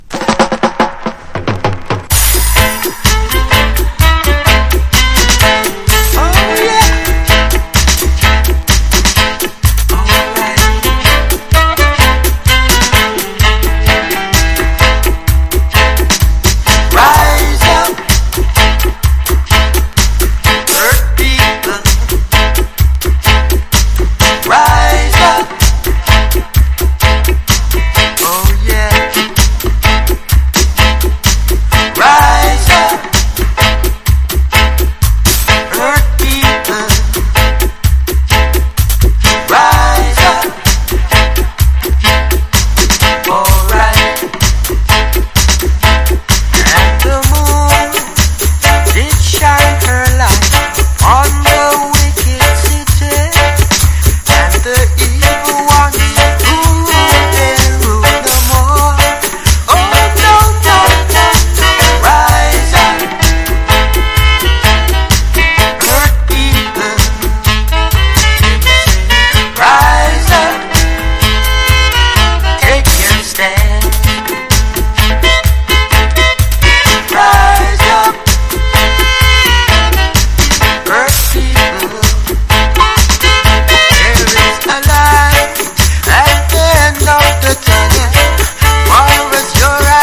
• REGGAE-SKA
コンシャスなマイナー・ステッパー・チューン！